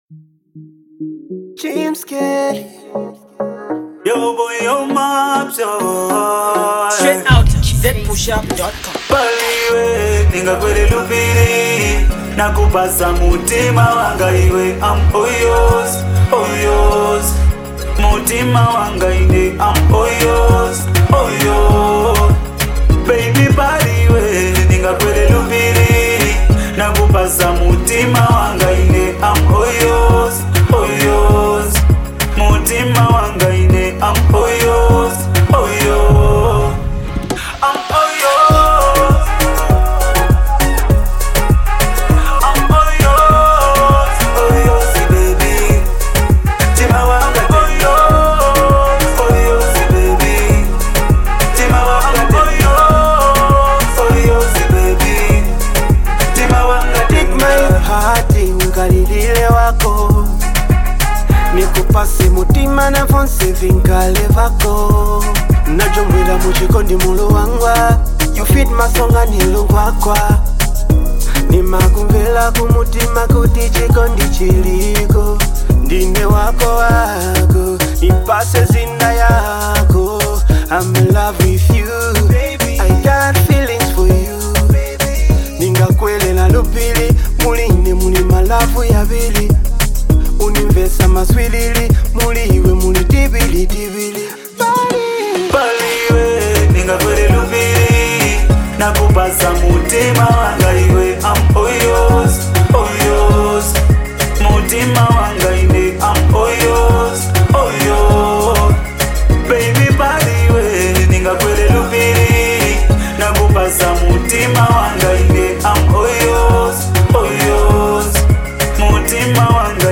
Brand new Love song done by Chipata’s freshest singer